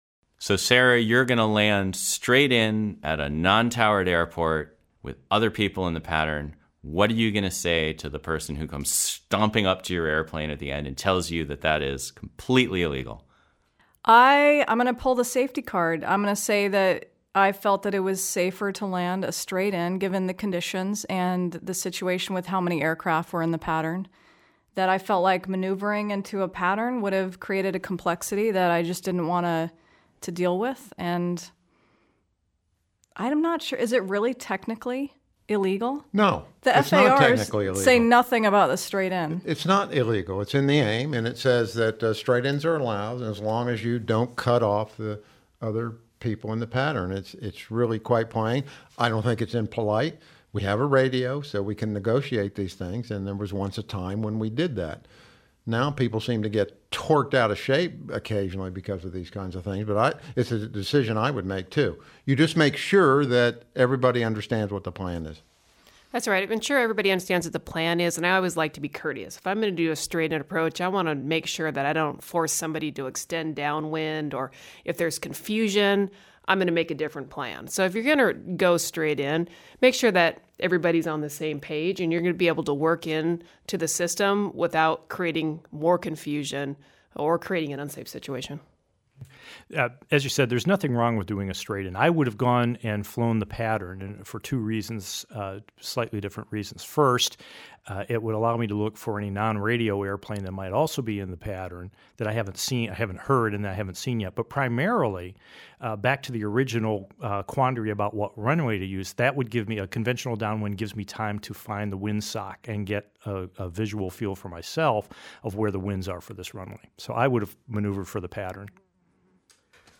Pattern Problems in Astoria_roundtable.mp3